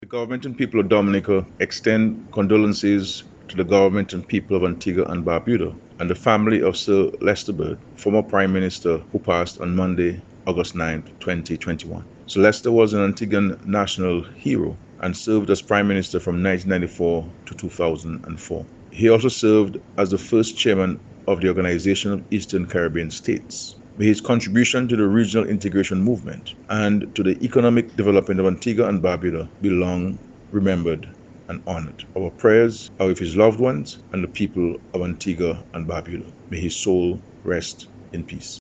Find attached voice clip of PM Skerrit.
PM-Skerrit-on-death-of-Sir-Lester-Bird.mp3